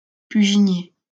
Puginier (French pronunciation: [pyʒinje]